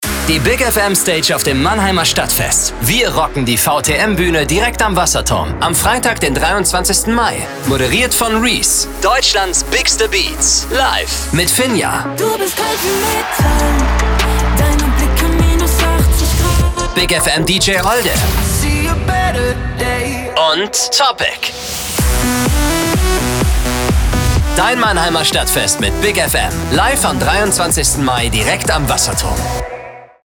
Spotbeispiele für die Vorbewerbung